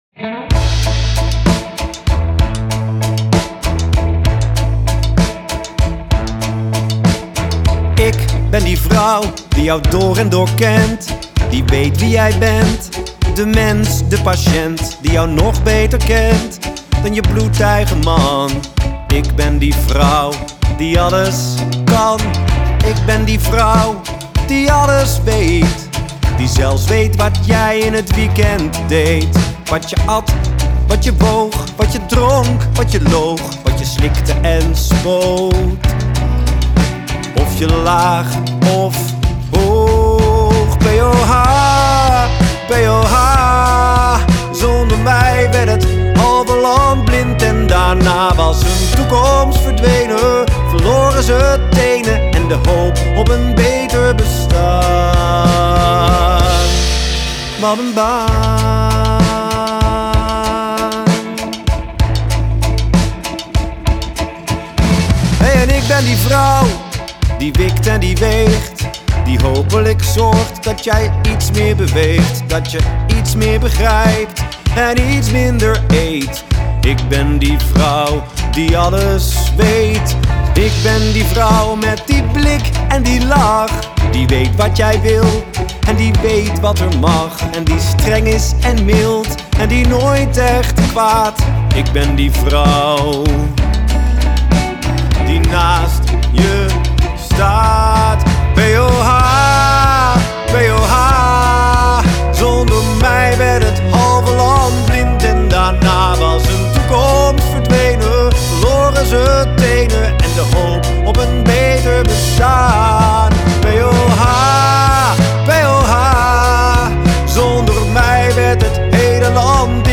Zang, gitaar
Gitaar
Bas, toetsen
Slagwerk